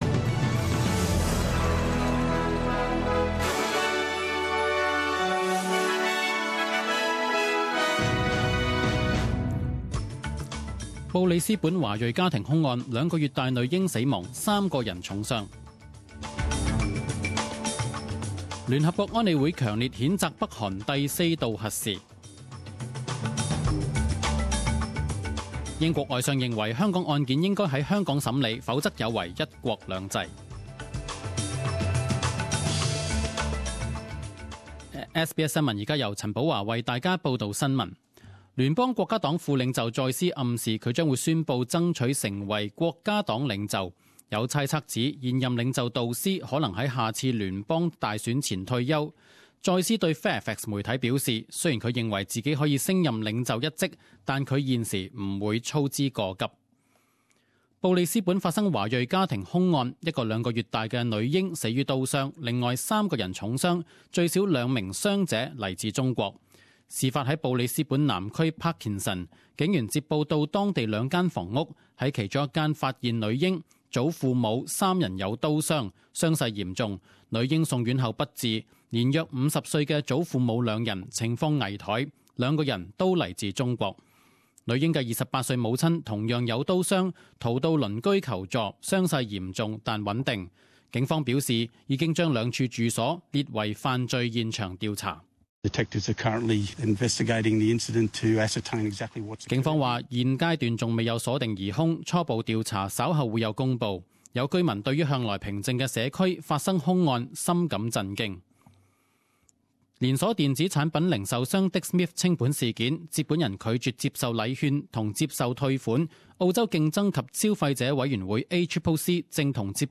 十点钟新闻报导（一月七日）